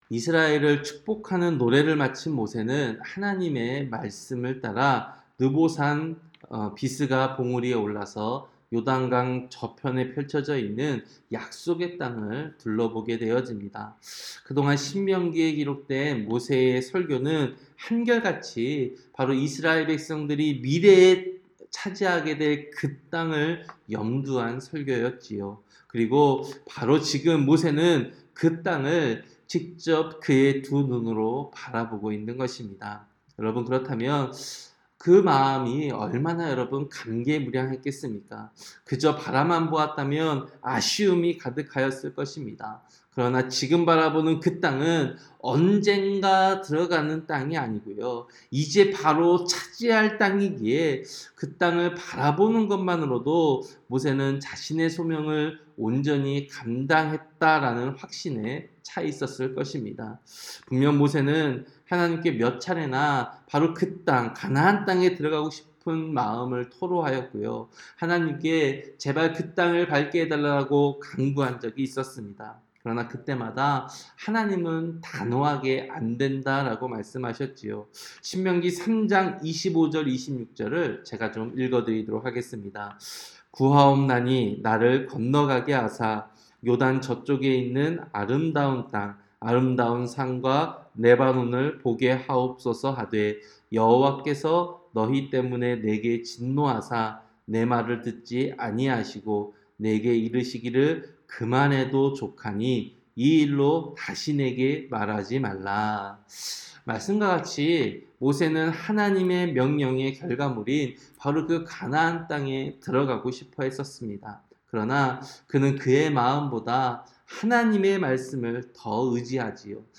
새벽설교-신명기 34장